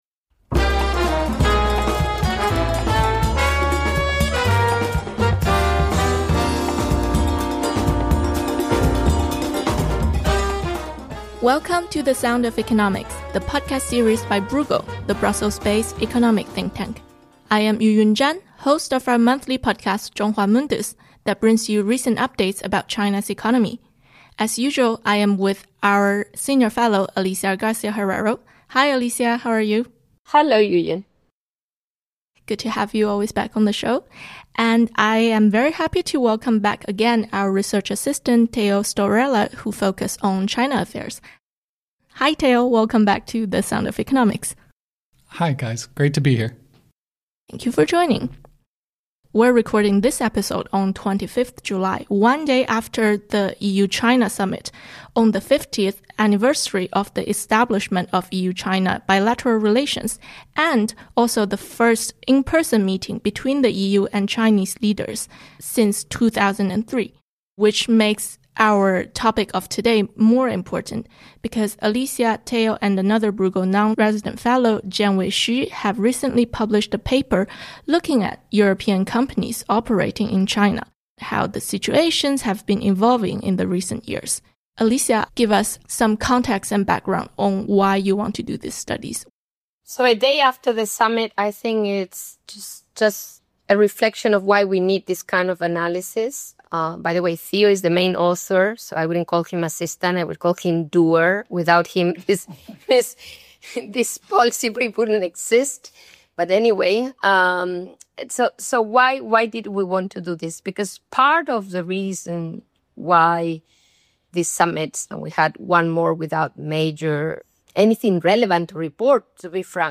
This conversation was recorded on 25 July 2025, just after the EU-China Summit.